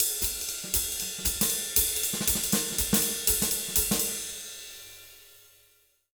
240SWING02-L.wav